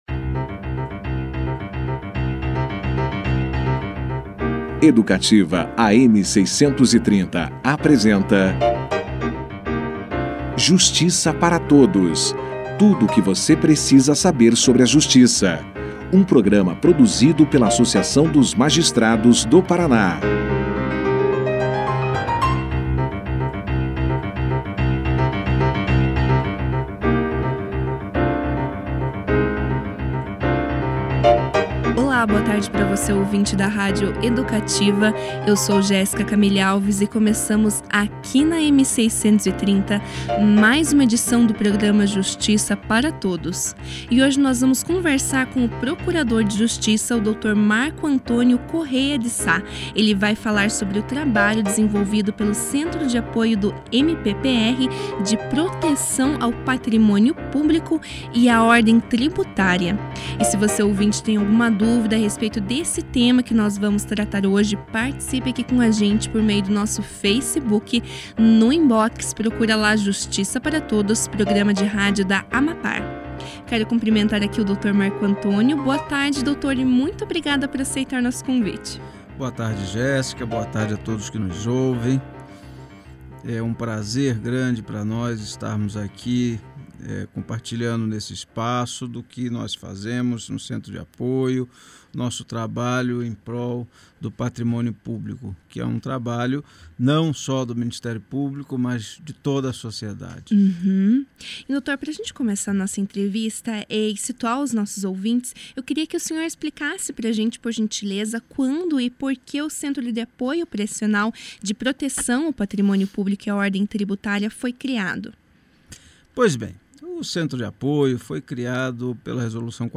Procurador de Justiça fala sobre proteção ao patrimônio público e a ordem tributária no Justiça para Todos